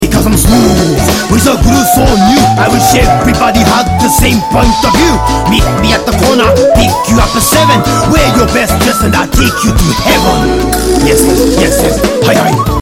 parappa2-smooth.mp3